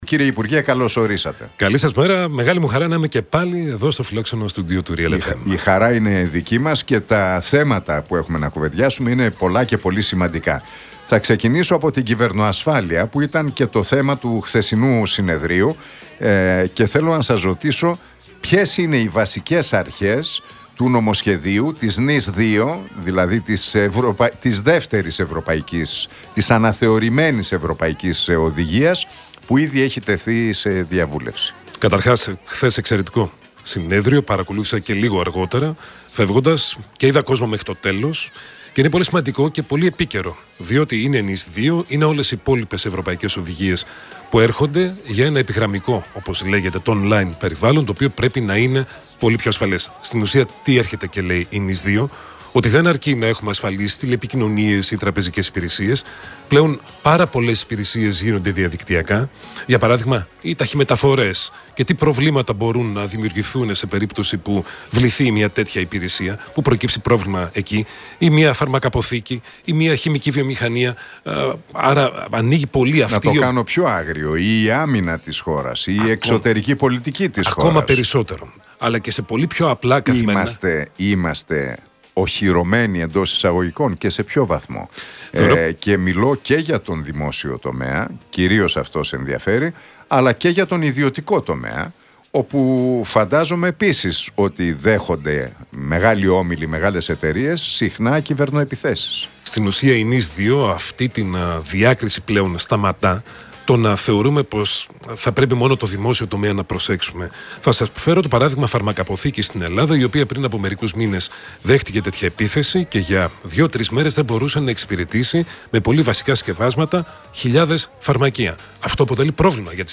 Βασικός πομπός που οδηγεί στην έκρηξη βίας μεταξύ ανηλίκων είναι το διαδίκτυο και τα social media, δήλωσε στον Realfm 97,8 και τον Νίκο Χατζηνικολάου ο υπουργός Ψηφιακής Διακυβέρνησης, Δημήτρης Παπαστεργίου. Επισήμανε ότι θα πρέπει οι πάροχοι μέσων κοινωνικής δικτύωσης να πιεστούν ώστε να είναι πιο προσεκτικοί στο περιεχόμενο που απευθύνεται σε νέους.